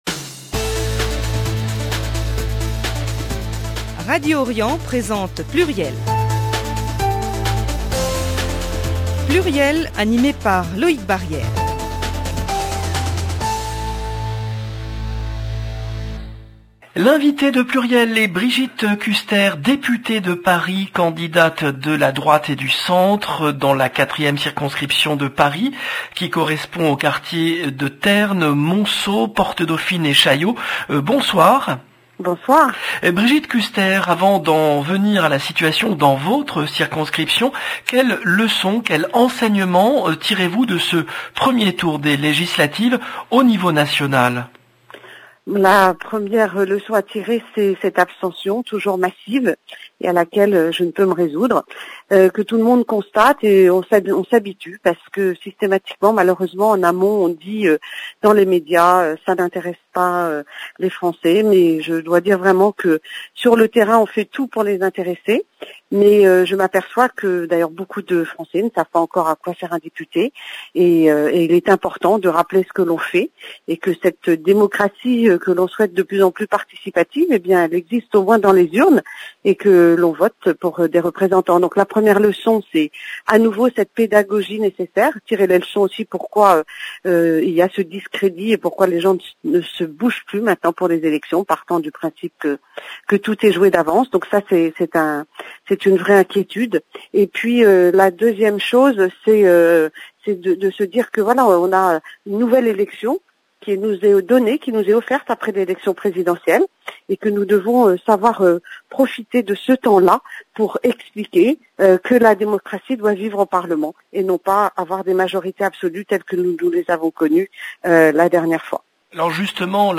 L’invitée de PLURIEL est Brigitte Kuster, députée de Paris, candidate de la droite et du centre dans la 4e circonscription de Paris